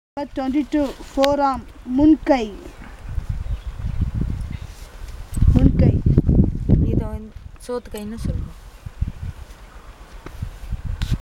Elicitation of words about human body parts - Part 7